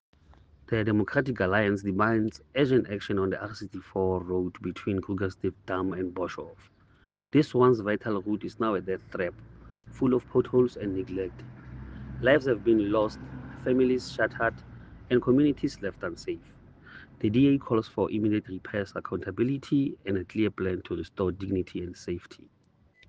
Sesotho soundbites by Cllr Ernest Putsoenyane.